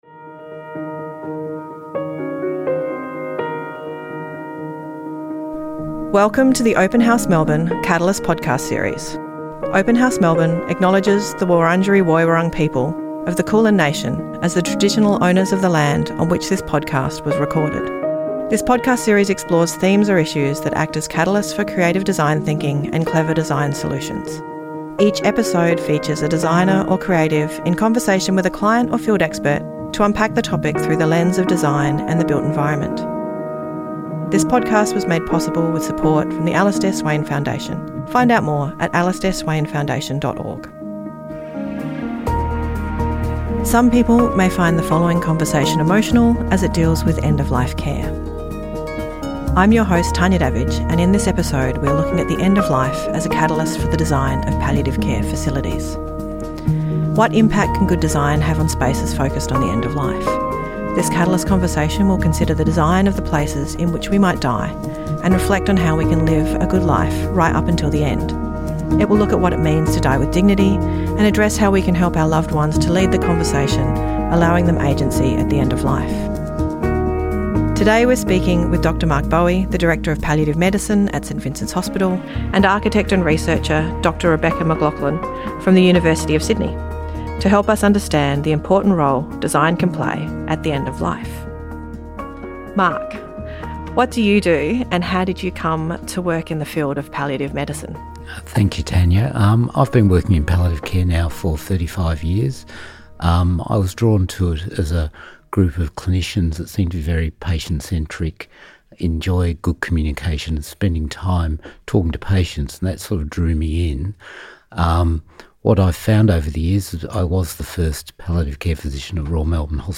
The Catalyst podcast series was recorded at The Push , Collingwood Yards.